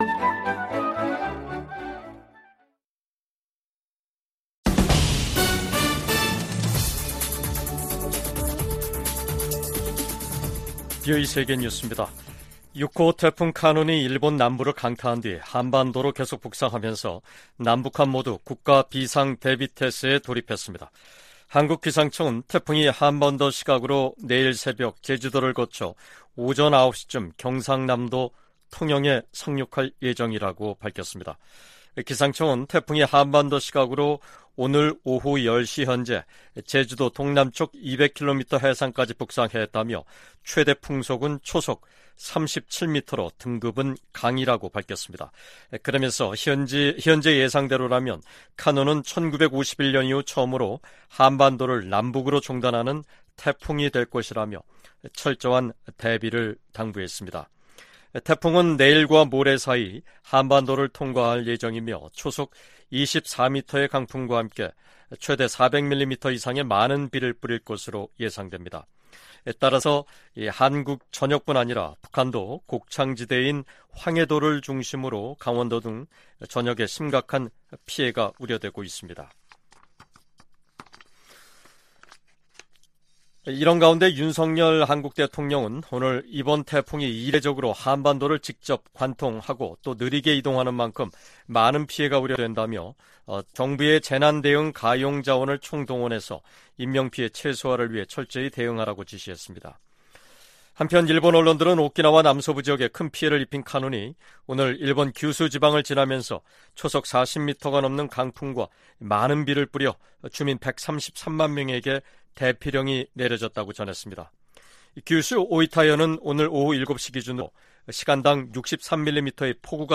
VOA 한국어 간판 뉴스 프로그램 '뉴스 투데이', 2023년 8월 9일 3부 방송입니다. 미 국방부는 북한과의 무기 거래는 불법이라며 대가가 따를 것이라고 경고했습니다. 중국 중고 선박이 북한으로 판매되는 사례가 잇따르고 있는 가운데 국무부는 기존 대북제재를 계속 이행할 것이라는 입장을 밝혔습니다. 다음 주 미국에서 열리는 미한일 정상회의를 계기로 3국 정상회의가 정례화될 가능성이 높다고 미국 전문가들이 전망했습니다.